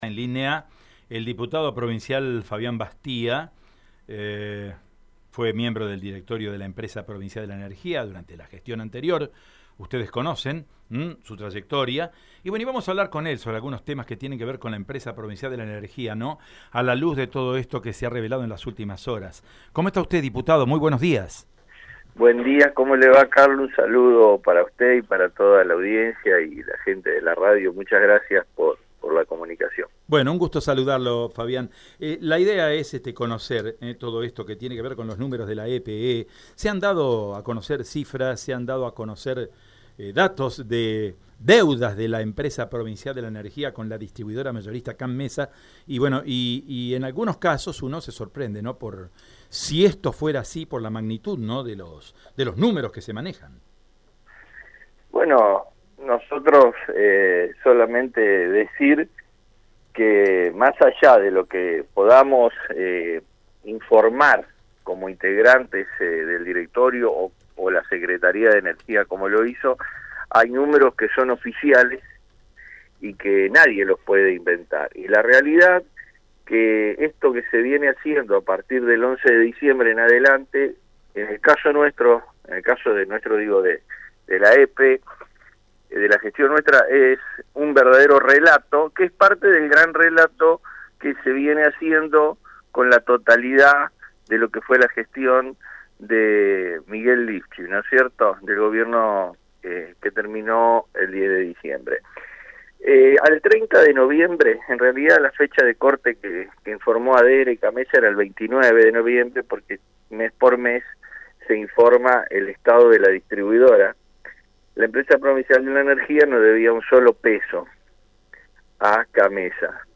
Hablamos-con-el-Diputado.mp3